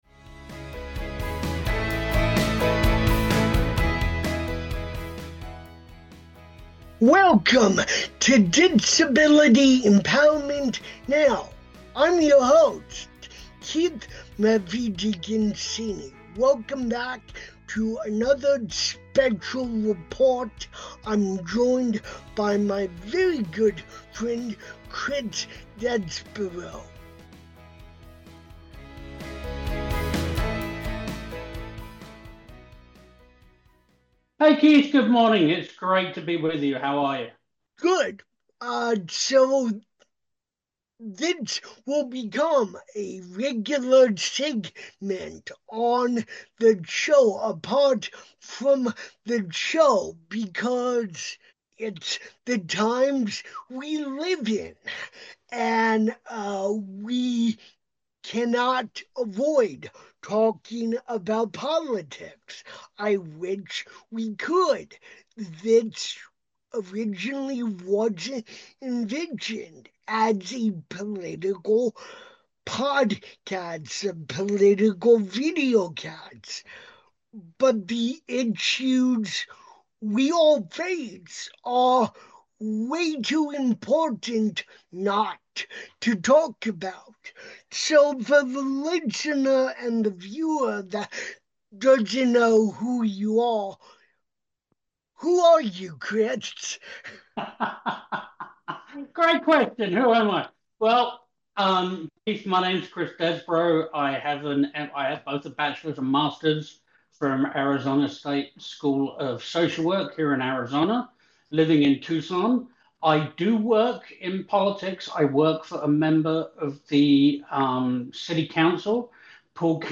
Season 4 Special Report 2 This Special Report focuses on the state of the disability community in current times, 2025.